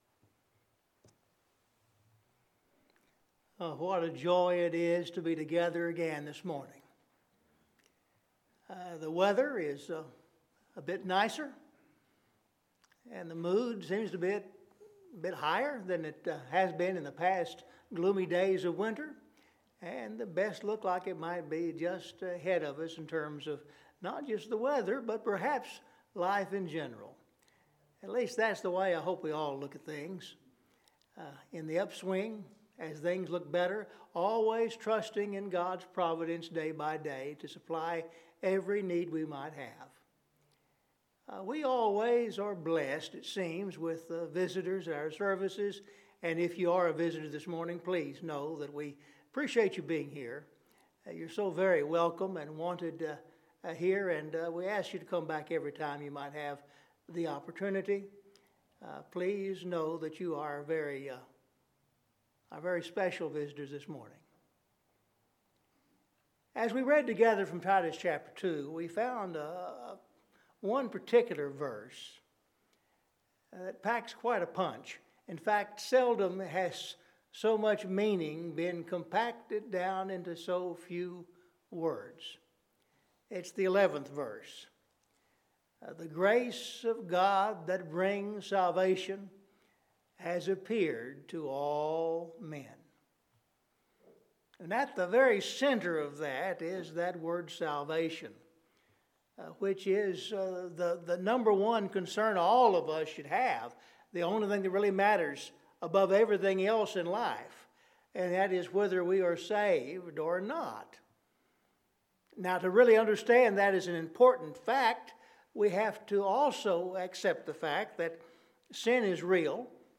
Scripture Reading